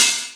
HI-HAT OP.wav